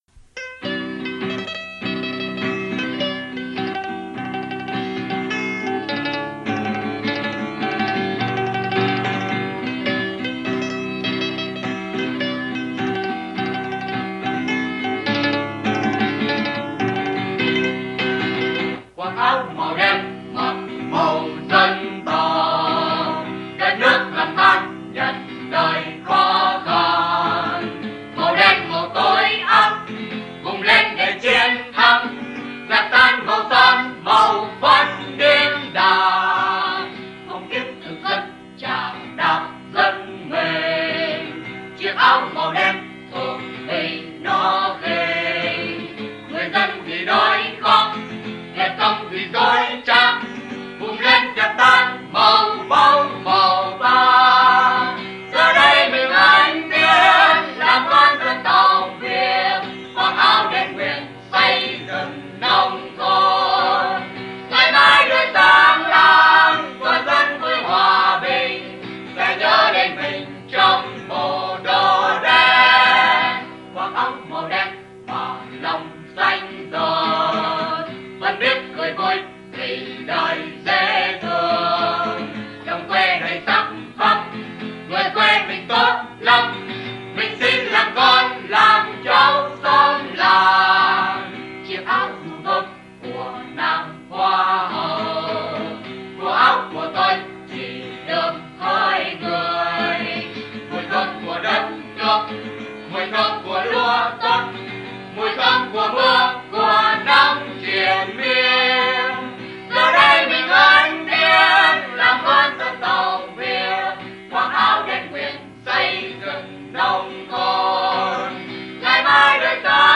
Nhạc Cách Mạng VNCH
hợp xướng